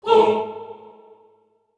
SouthSide Chant (50).WAV